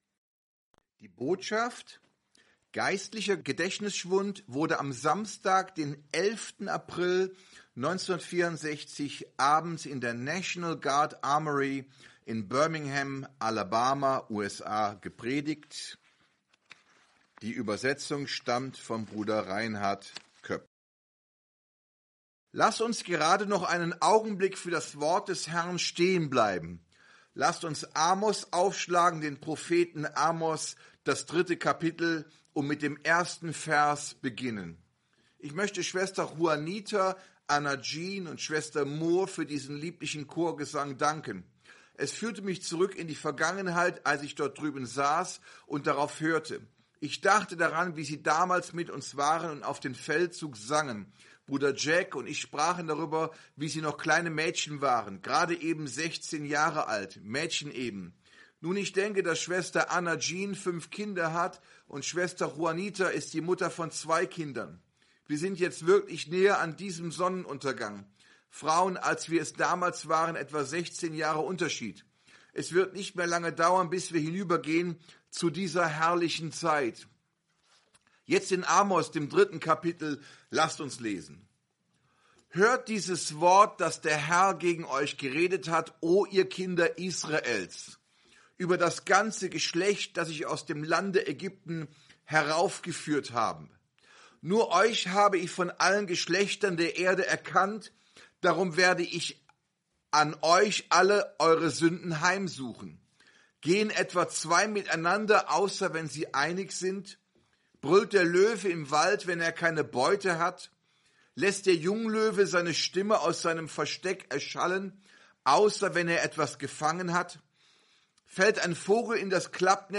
aufgezeichneten Predigten